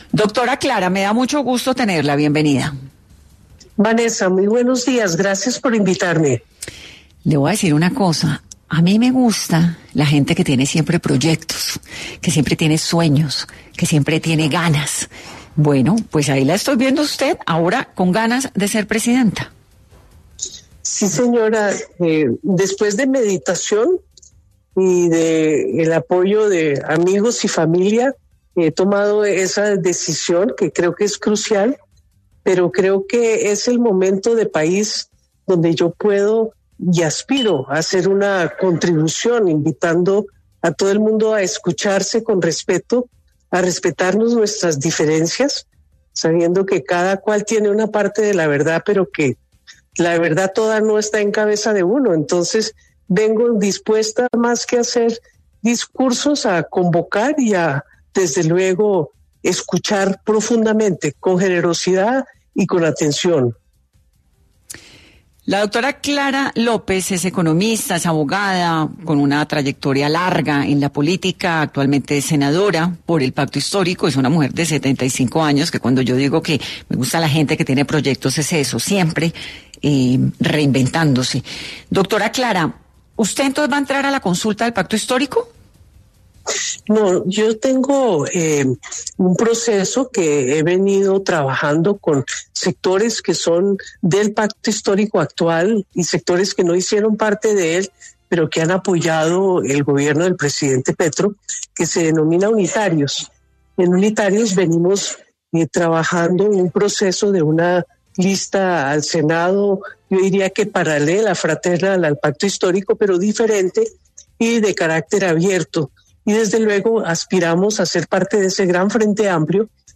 La senadora Clara López habló sobre su precandidatura a la presidencia en #10 AM de Caracol Radio